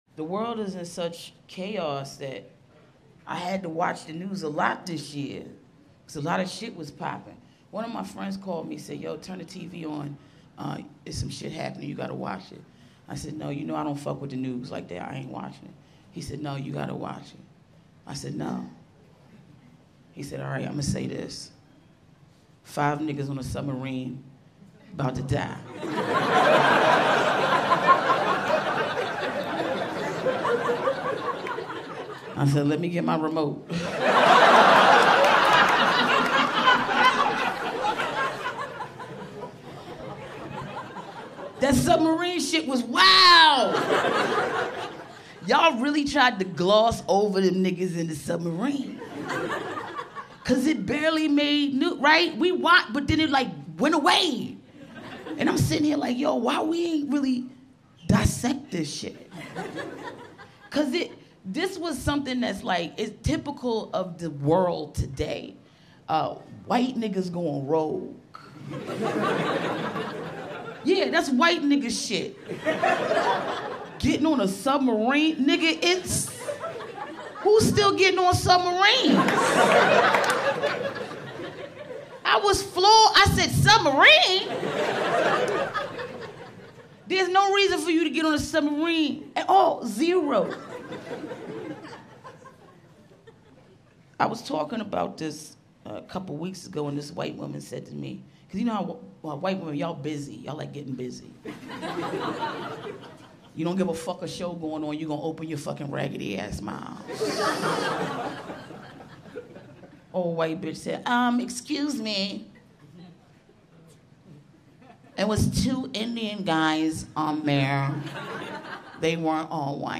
Billionaire Buffoonery: A Stand-Up Roast with Yamaneika Saunders 🤣